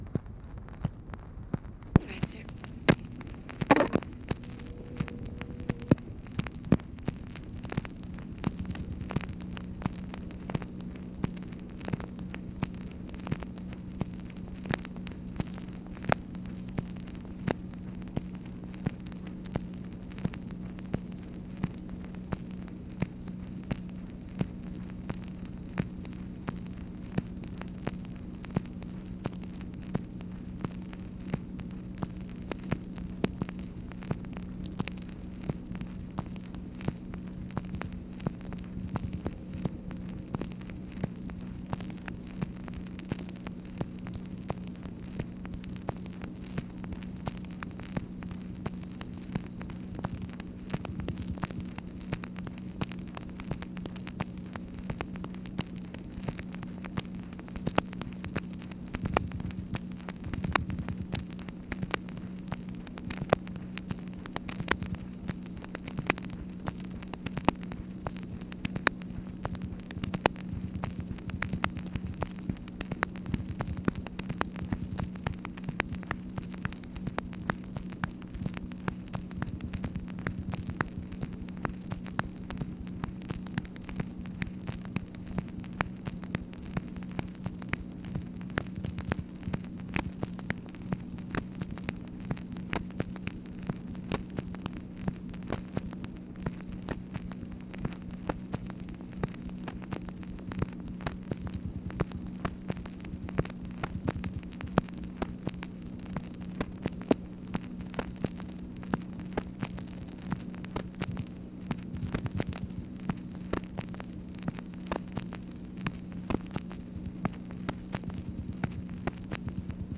"ALL RIGHT, SIR" ONLY SPOKEN WORDS
Conversation with OFFICE SECRETARY and UNKNOWN, March 18, 1964
Secret White House Tapes | Lyndon B. Johnson Presidency